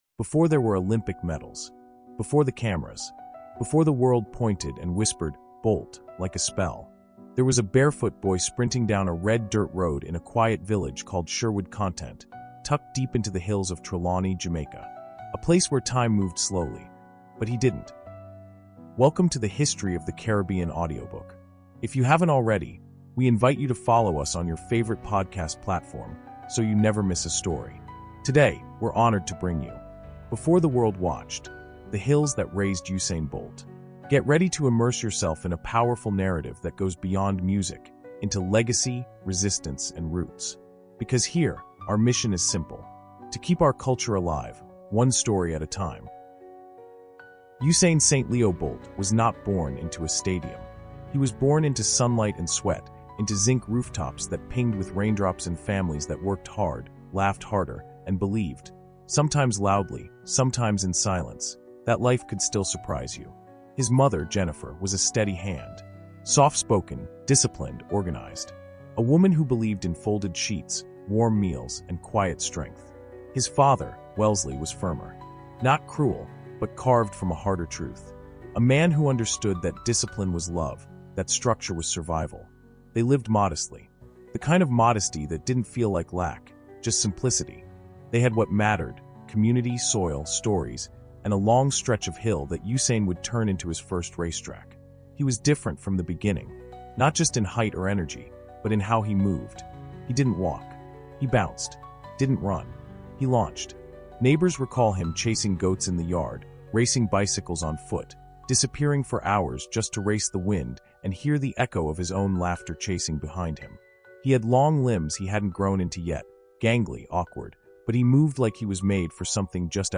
We explore the environment, values, and cultural pulse that shaped a global legend. Through vivid storytelling and cultural context, listeners are invited to see how the rawness of rural life fueled the flame of one of the greatest athletes in human history.